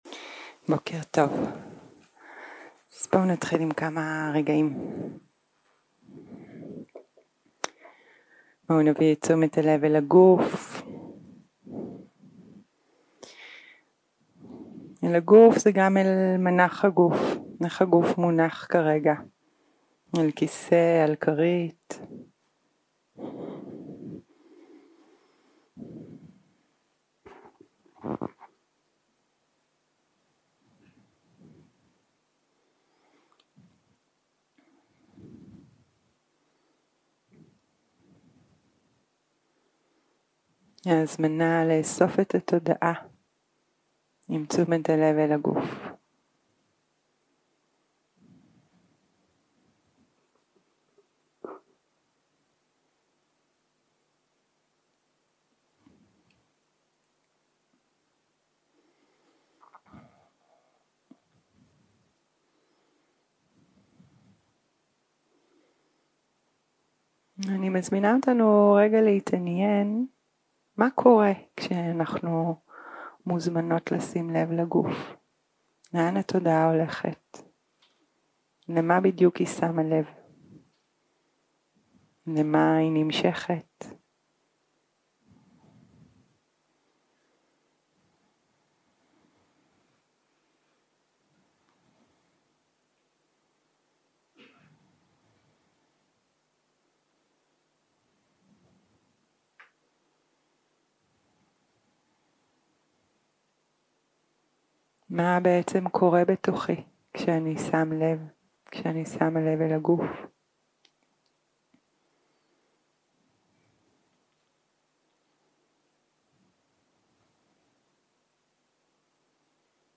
יום 3 - הקלטה 5 - בוקר - הנחיות למדיטציה - עבודה עם אי נחת וכאב של הגוף 0:00 0:00 סוג ההקלטה: סוג ההקלטה: שיחת הנחיות למדיטציה שפת ההקלטה: שפת ההקלטה: עברית